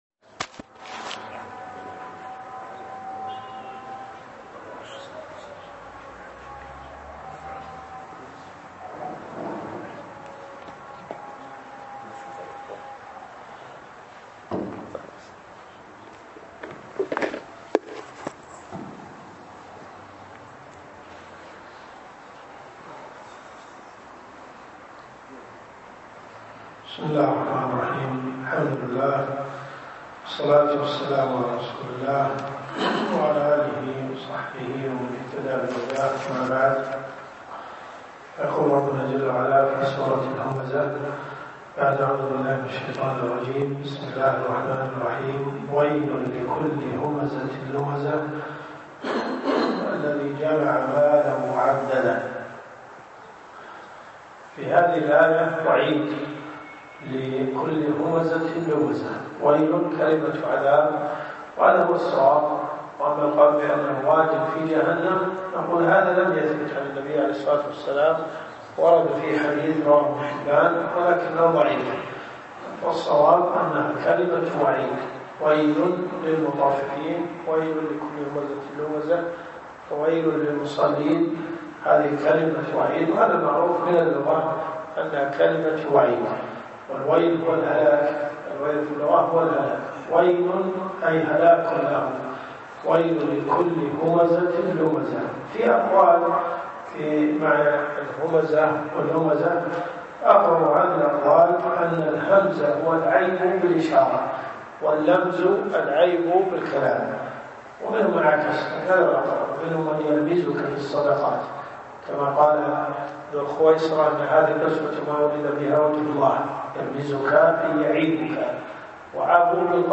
دروس صوتيه ومرئية تقام في جامع الحمدان بالرياض - فتاوى .